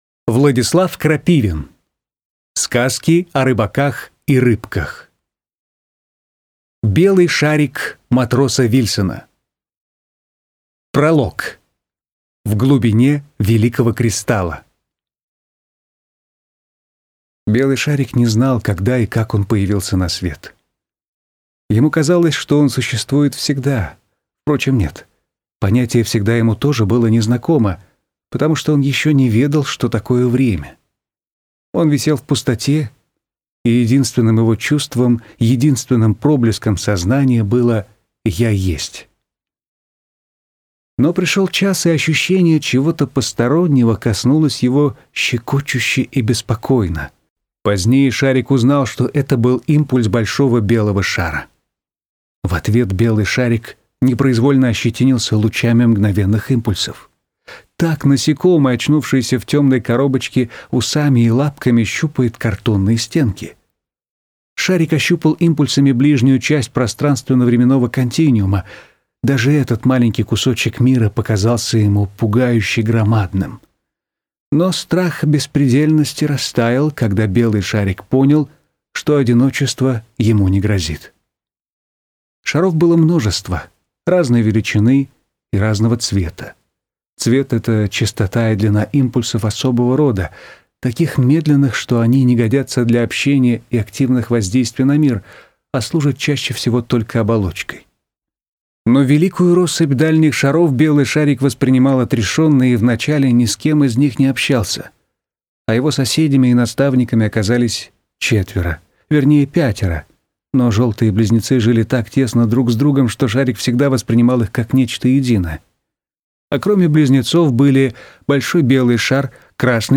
Аудиокнига Белый шарик Матроса Вильсона | Библиотека аудиокниг